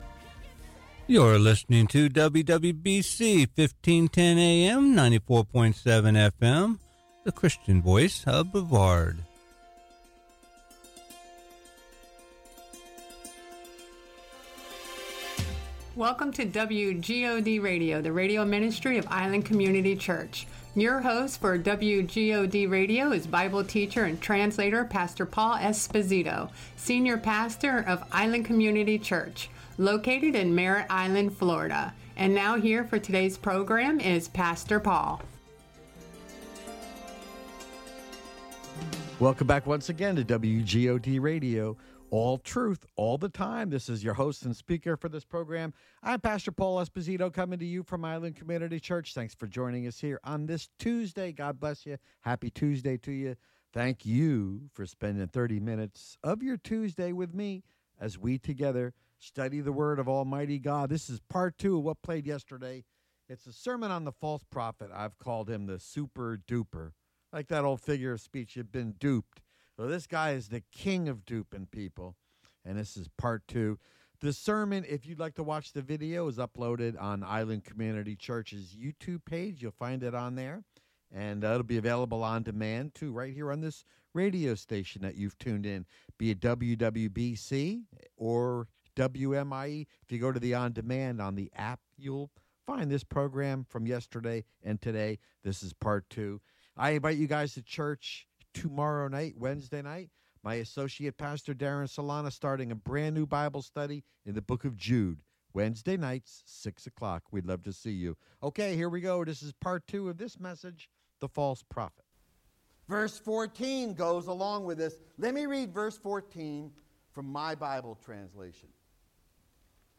Sermon: "The False Prophet" Rev. 13 Part 2